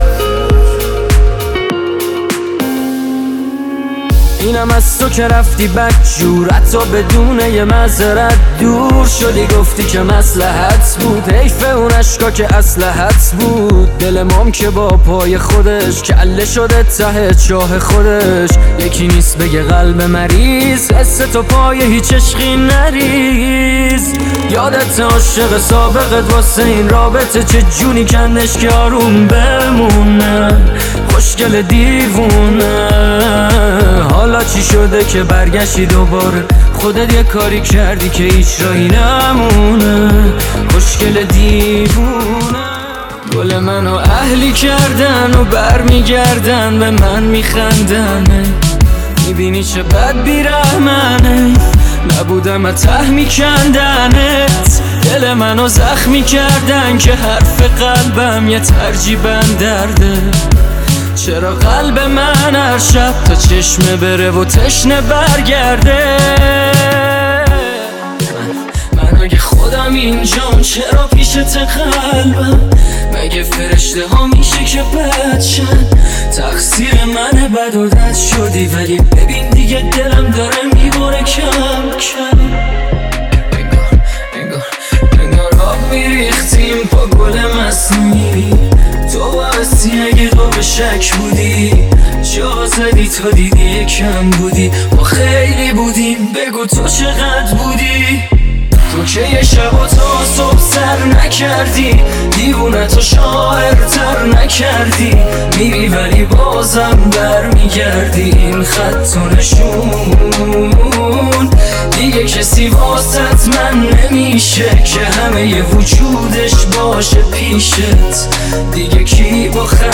میکس موزیک بسیار زیبا